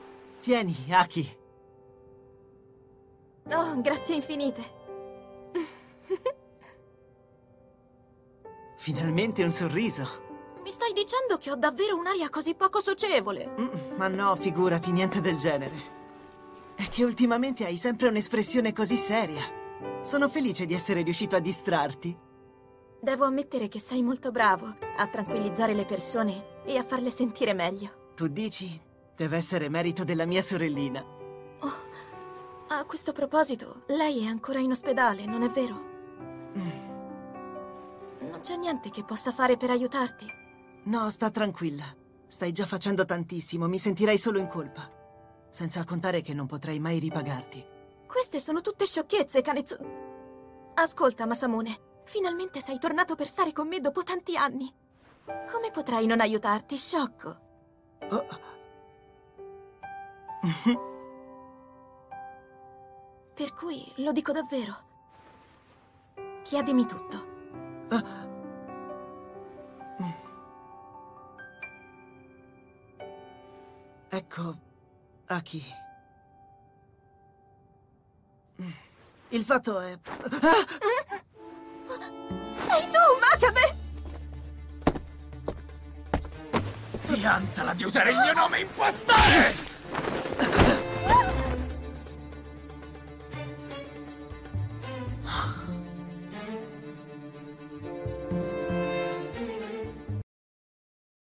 nel cartone animato "Masamune-kun's Revenge", in cui doppia Kanetesugu Gasou.